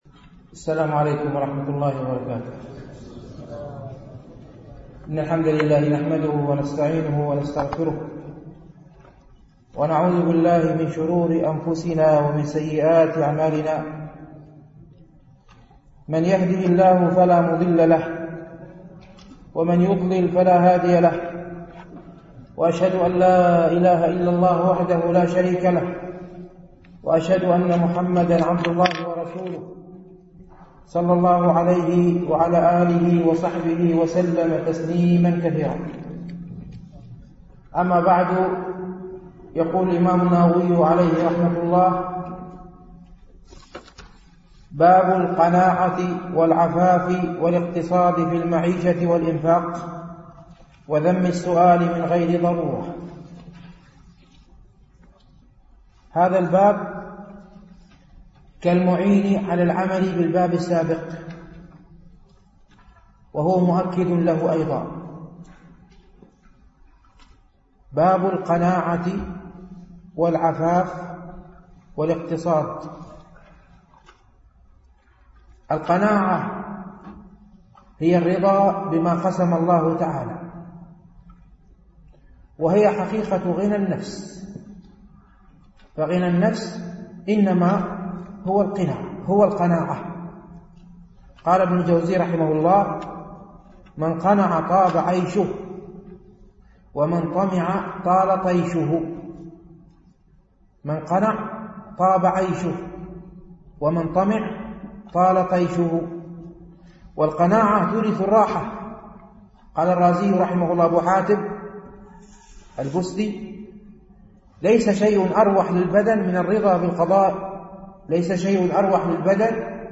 شرح رياض الصالحين - الدرس السابع والأربعون بعد المئة